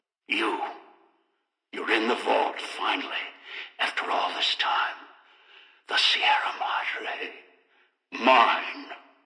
Category:Dead Money audio dialogues Du kannst diese Datei nicht überschreiben.